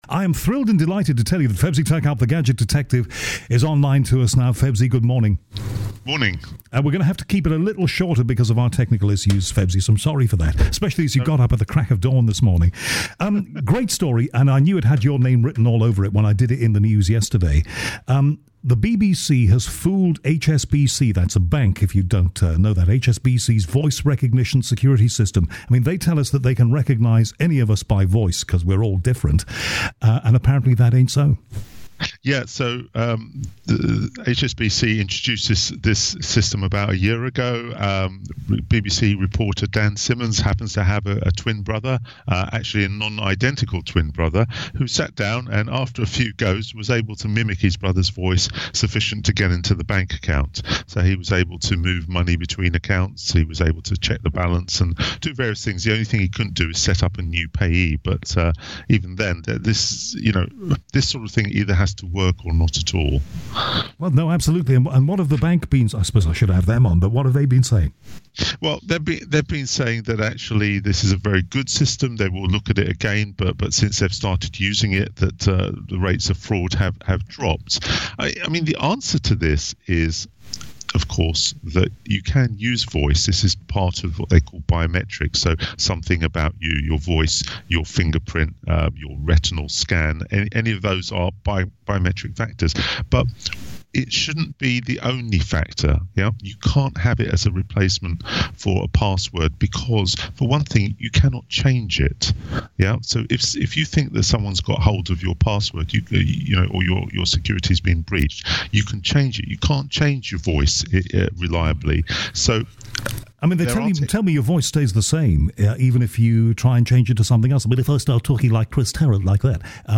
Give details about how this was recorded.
20th May 2017 - The Latest Tech News on BBC Radio Berkshire 2017-05-21